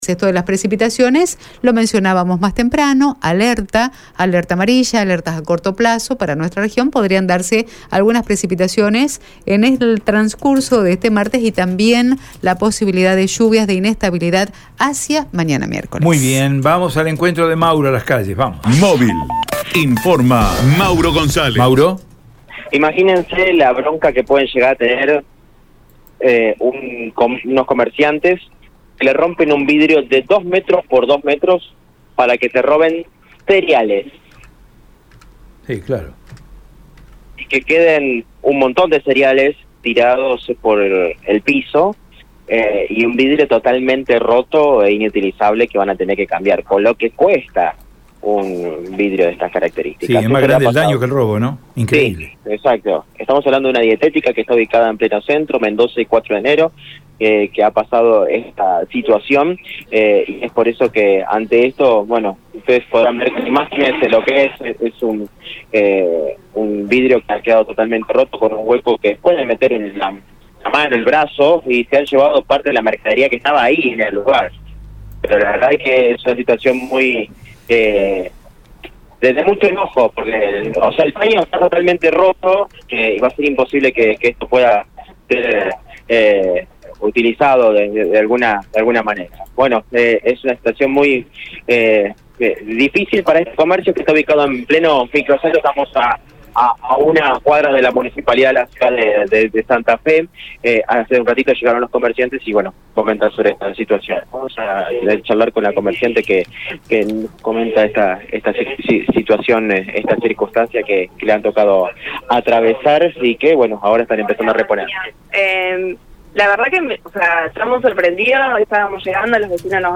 «Estamos sorprendidos. Los vecinos nos avisaron. Es el robo de algunas bolsiltas de cereales que tenemos a la vista. Y rompieron el vidrio», contó una empleada en el móvil de Radio EME.